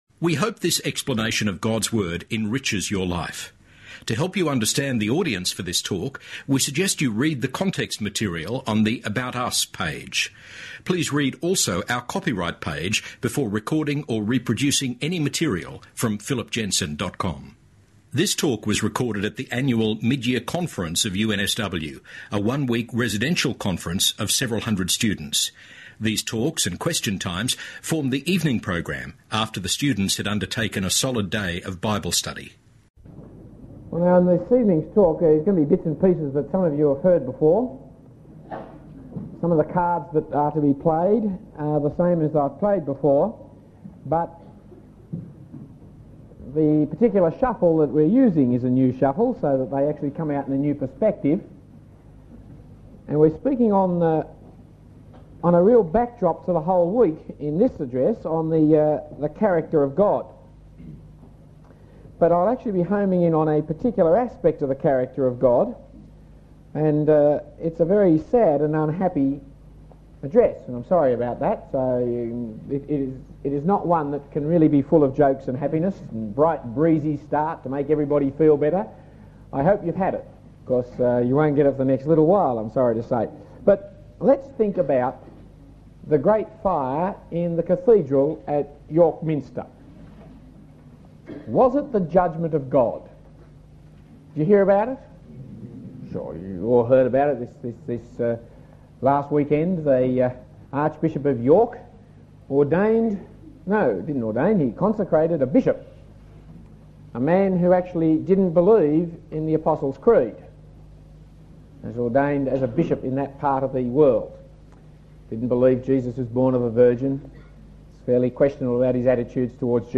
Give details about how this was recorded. Talk 1 of 6 in the series: Christ’s Death – My Life given at UNSW Mid Year Conference in 1984. (Apologies for the poor sound quality)